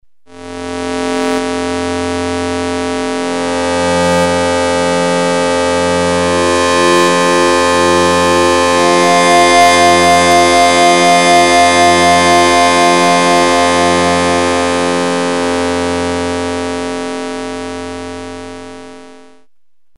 描述：极端/强烈的正弦波，嗡嗡声
Tag: 120 bpm Electronic Loops Fx Loops 3.35 MB wav Key : Unknown